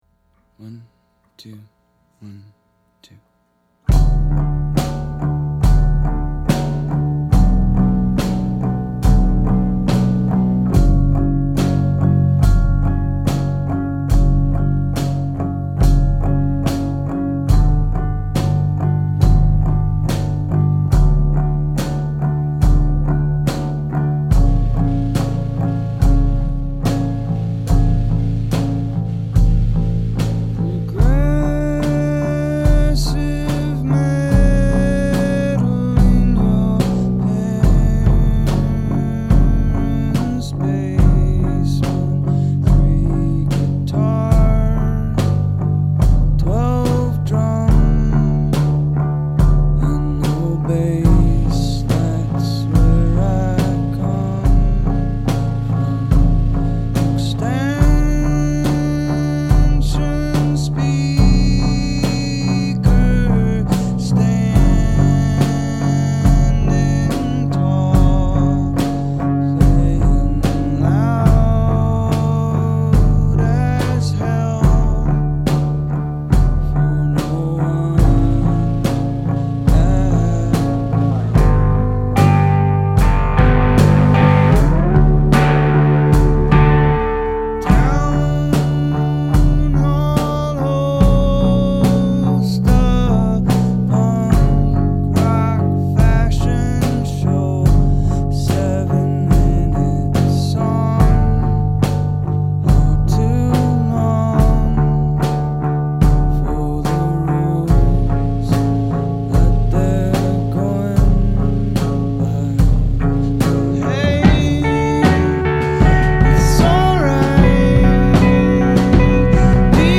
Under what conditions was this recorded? The songs were recorded live